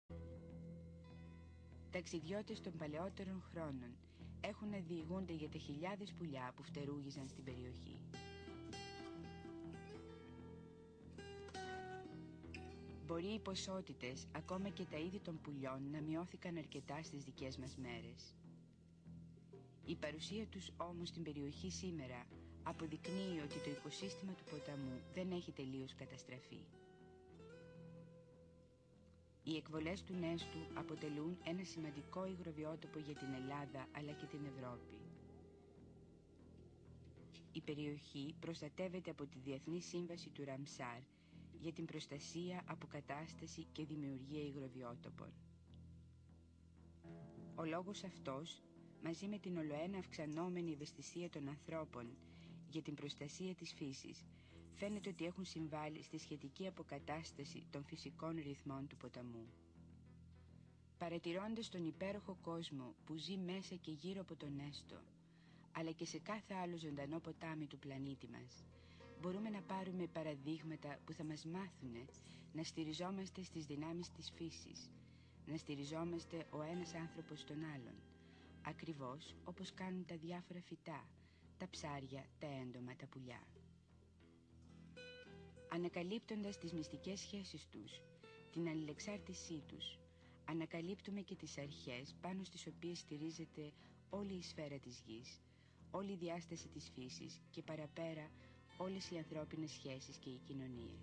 Πηγή: Τηλεοπτική σειρά (ντοκυμαντέρ)